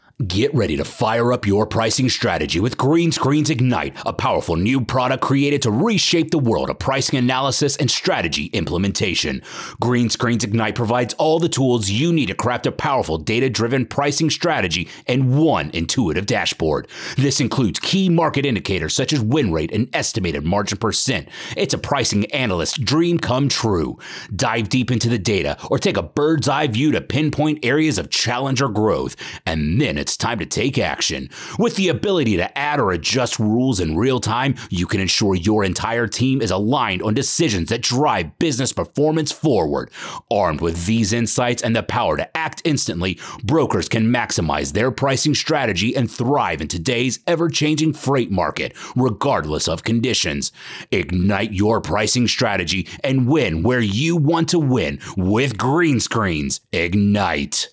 Product Announcement Video
Middle Aged